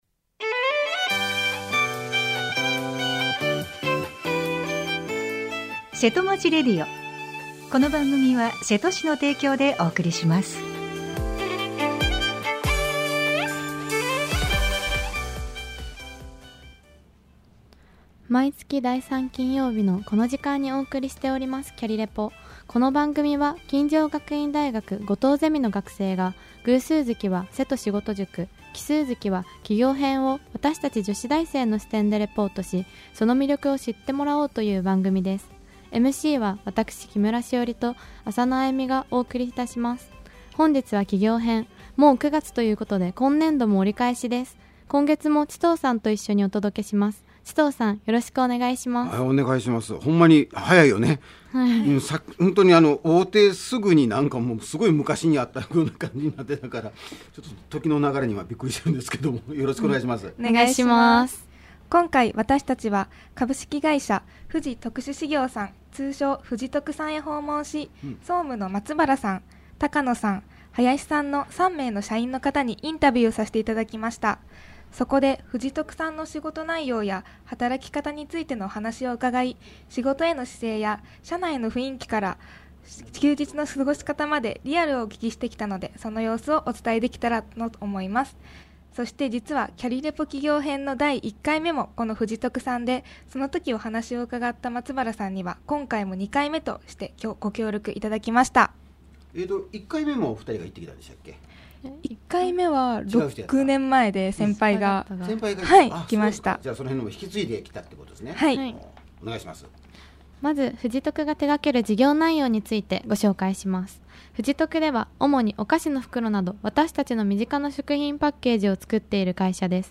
音声ブログ » 生放送